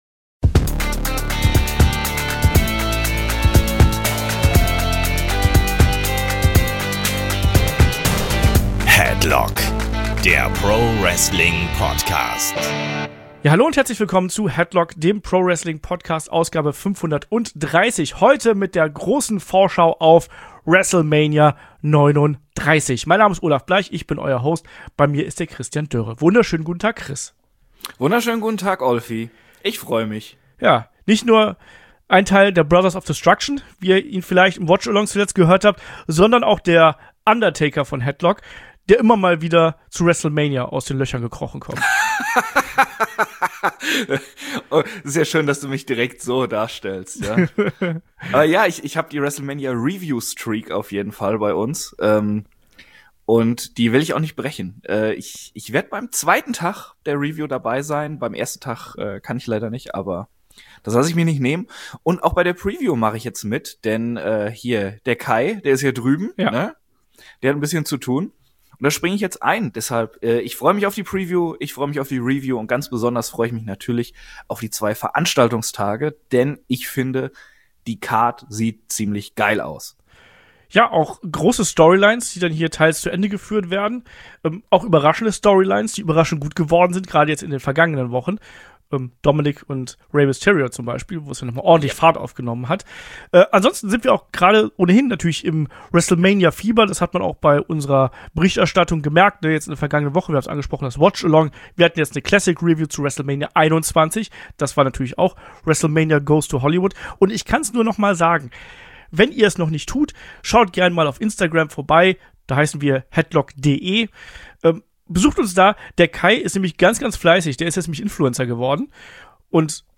Headlock ist die Wrestling-Gesprächsrunde: Hier plaudert man nicht nur über das aktuelle WWE-Geschehen, sondern wirft auch einen Blick über den Tellerrand.